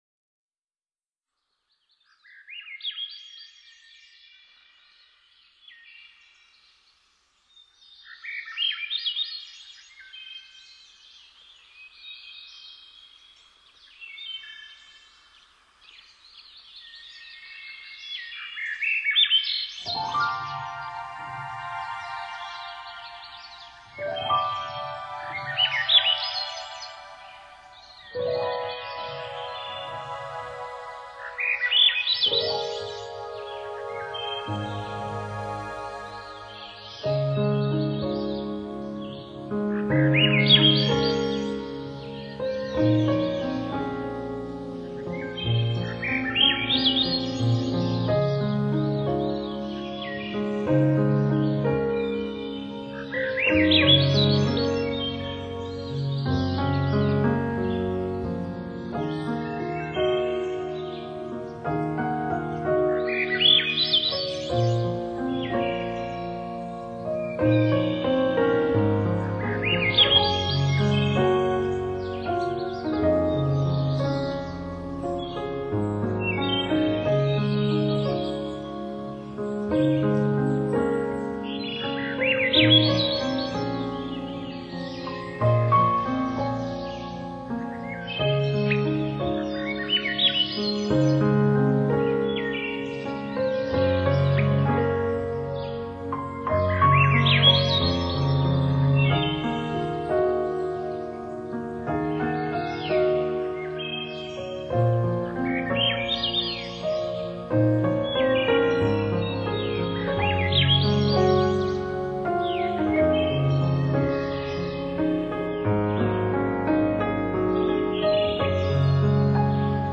每一滴雨珠 每一声琴音
第一、八首：史温森夜鸫、黄褐森鸫。